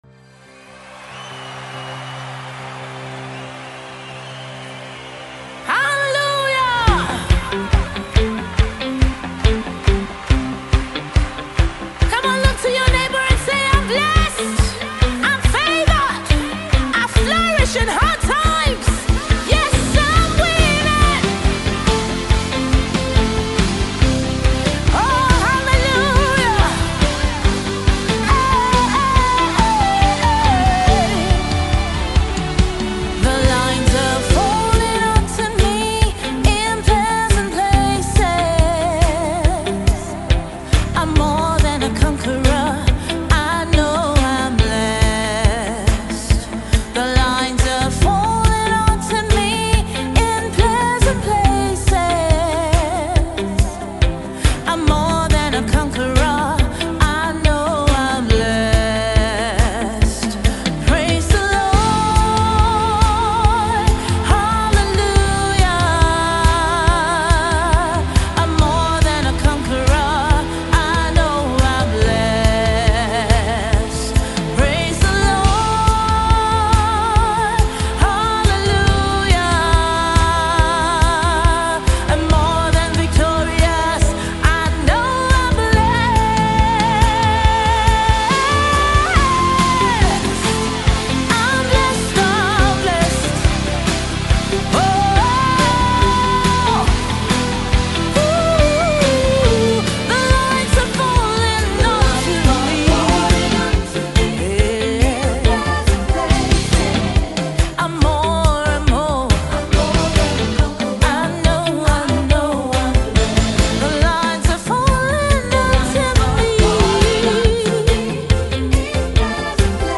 Gospel music singer
An anthem of praise.